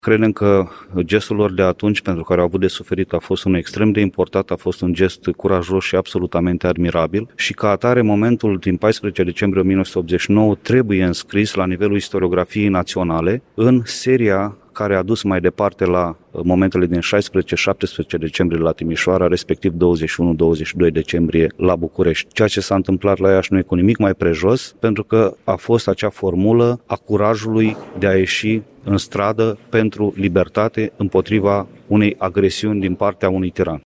Astăzi, la Iași, în Piața Unirii din localitate, s-a desfășurat un ceremonial de depuneri de coroane de flori în memoria Momentului Zero al Revoluției Române.
Prezent la manifestări, președintele Institutului de Investigare a Crimelor Comunismului, Daniel Șandru, a adăugat că gestul revoluționarilor de la Iași a fost admirabil în contextul totalitarismului existent în România la acea vreme.